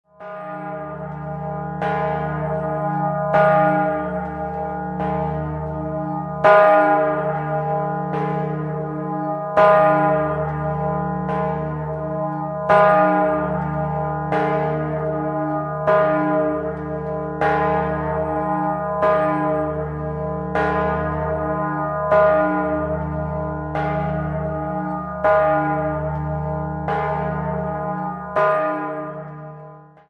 Sie ist die größte Glocke Tirols und das zweittontiefste Instrument, das die Gießerei Grassmayr in Innsbruck je gegossen hat.
Schlagton: es°+1 Die Glocke wiegt mit Joch 12.300 kg, wurde 1997 gegossen und hat eine Höhe von 2,51 m und einen Durchmesser von 2,54 m.